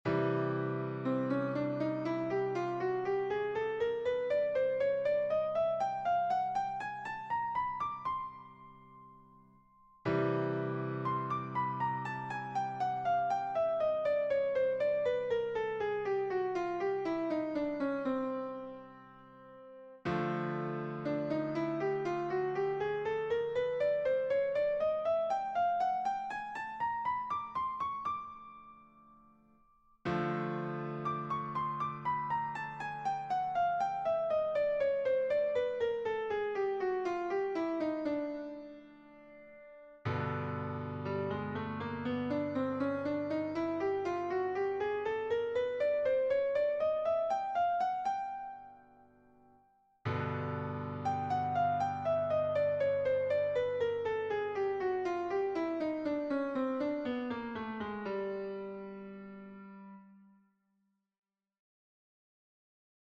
Improvisation Piano Jazz
Gamme chromatique Barry Harris
• On insère un chromatisme entre chaque ton de la gamme
Quel que soit le mode, on retrouvera la même chose pour une gamme donnée ( ici DO ).